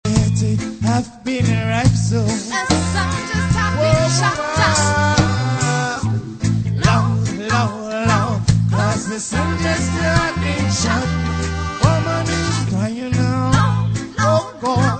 reggae roots